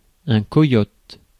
Prononciation
Prononciation France: IPA: /kɔ.jɔt/ Le mot recherché trouvé avec ces langues de source: français Traduction Contexte Substantifs 1.